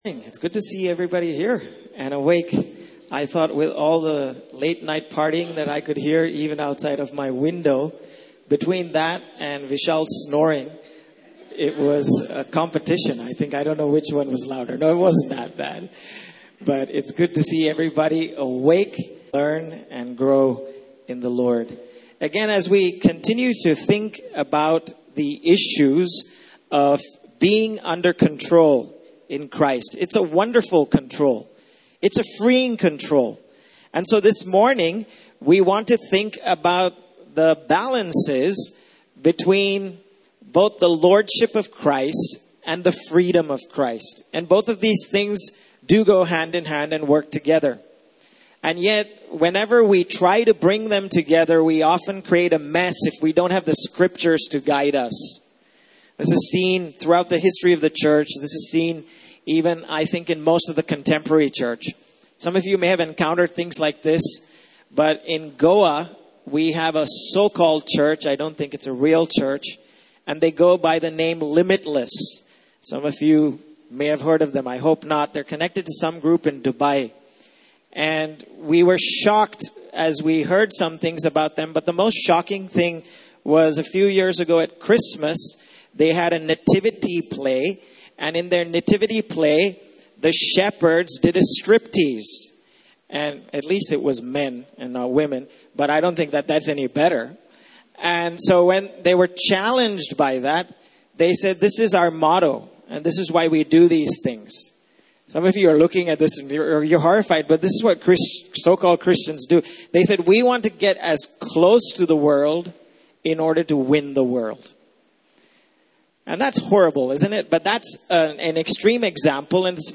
Service Type: Main Session